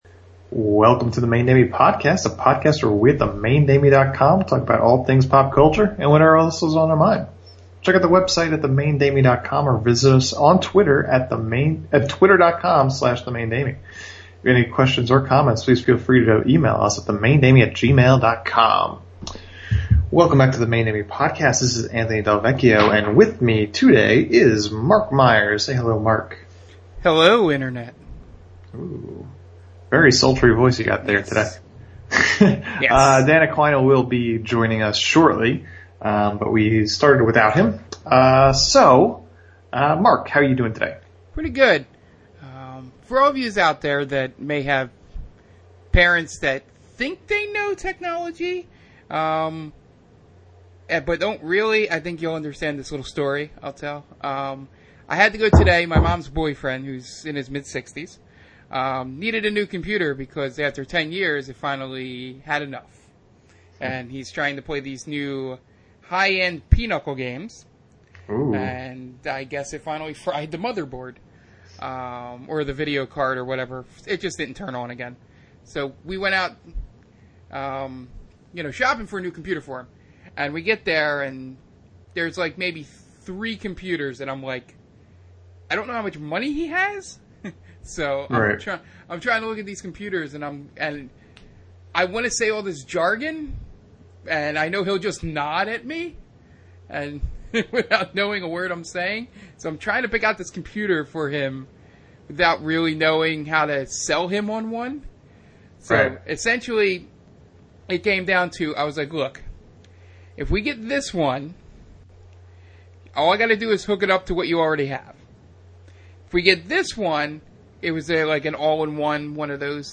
On this unusually rant-y episode of The Main Damie podcast, our fearless trio discusses everything from professional wrestling to San Diego Comic-con.